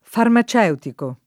vai all'elenco alfabetico delle voci ingrandisci il carattere 100% rimpicciolisci il carattere stampa invia tramite posta elettronica codividi su Facebook farmaceutico [ farma ©$ utiko ; non - © e 2 tiko ] agg.; pl. m. ‑ci